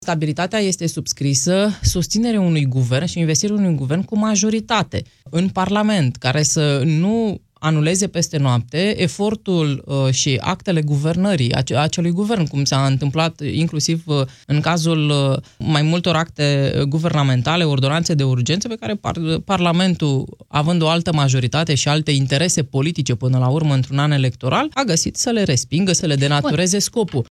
Ordonanţa de urgenţă privind organizarea alegerilor va fi transformată în proiect de lege, a declarat în emisiunea Piața Victoriei, senatoarea PNL Iulia Scântei.